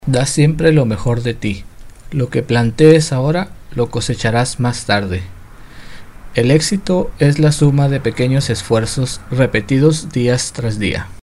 西班牙语中年沉稳 、男专题片 、宣传片 、纪录片 、广告 、飞碟说/MG 、课件PPT 、工程介绍 、绘本故事 、动漫动画游戏影视 、150元/百单词男西01 拉丁美洲西班牙语男声 干音2 沉稳